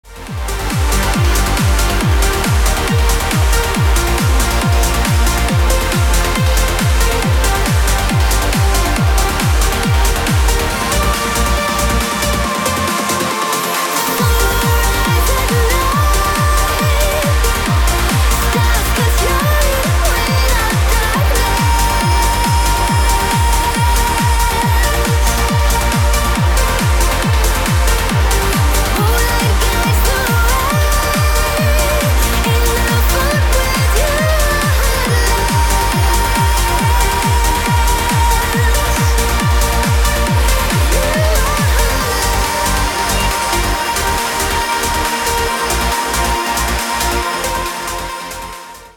• Качество: 320, Stereo
громкие
женский вокал
dance
электронная музыка
красивый женский голос
Trance
vocal trance